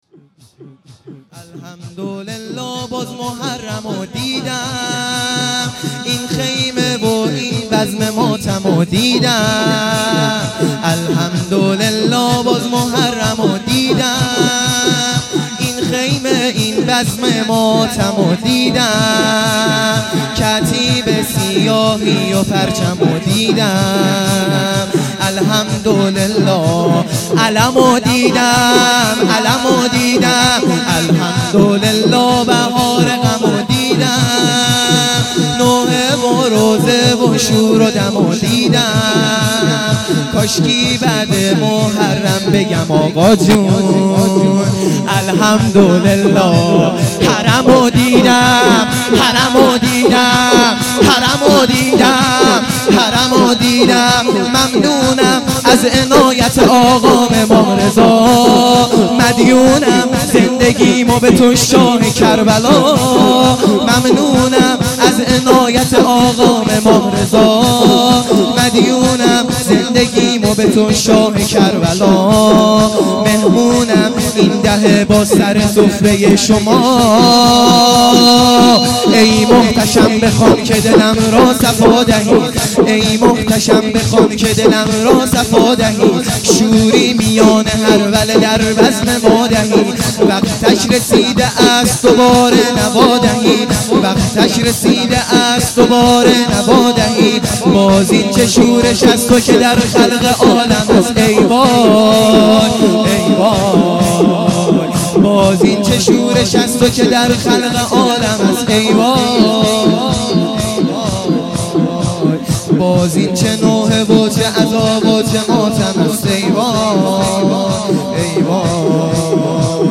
شور
شب اول محرم الحرام